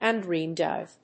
音節un・dreamed‐of 発音記号・読み方
/`ʌndrémtὰv(米国英語), `ʌndrém`ɔv(英国英語)/